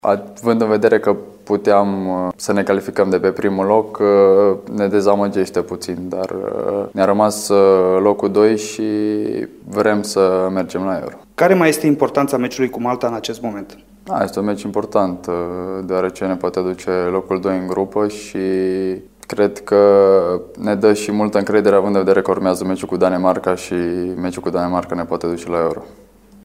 Mijlocașul timișorean Marius Marin a vorbit la FRF TV despre dezamăgirea eșecului din Ucraina, dar și despre jocurile cu maltezii și danezii, care pot asigura accesul reprezentativei lui Mutu la Europeanul de tineret de anul viitor: